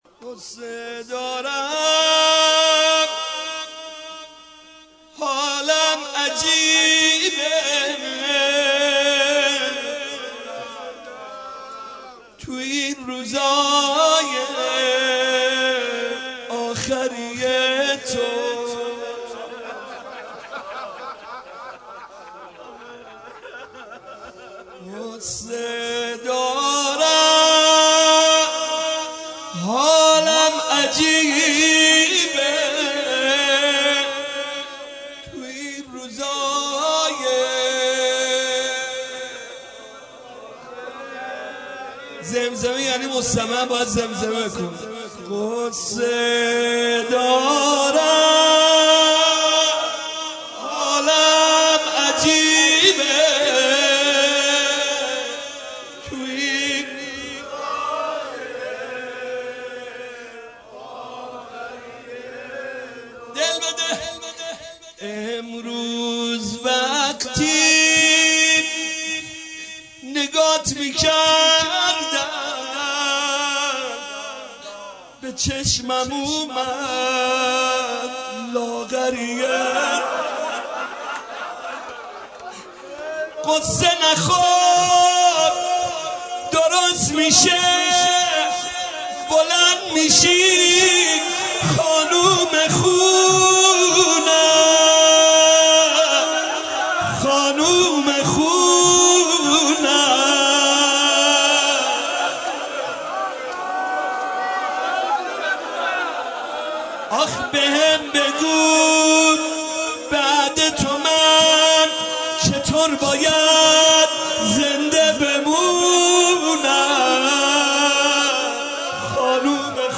مداحی فاطمیه 1395
[شب چهارم فاطمیه اول] [روضه]